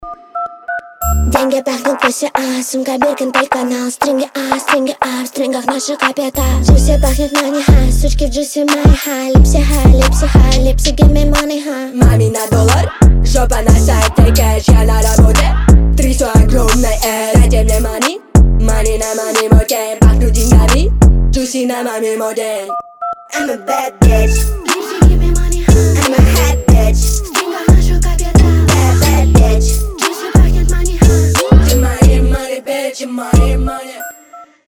басы
качающие
молодежные